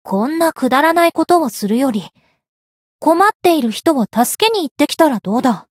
灵魂潮汐-密丝特-互动-不耐烦的反馈2.ogg